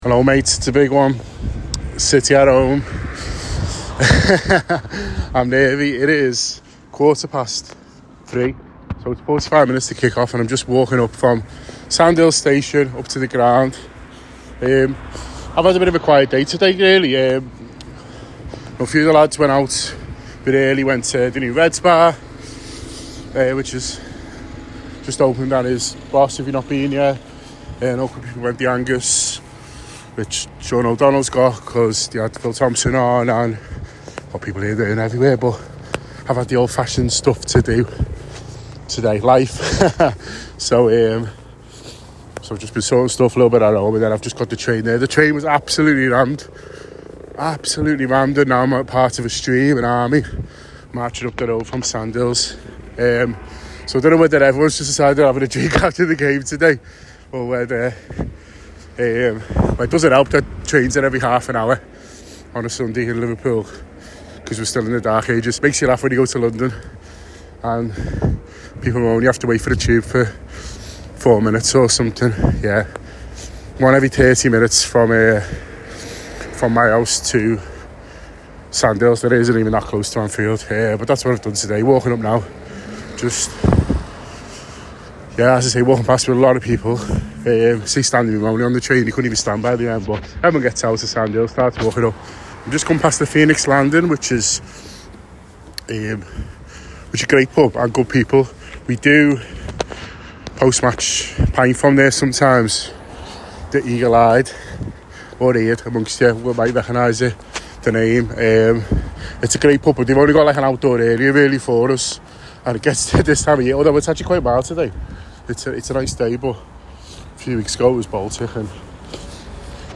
The Anfield Wrap’s Match Day Diary speaks to supporters at Anfield on the day Liverpool faced Manchester City at Anfield.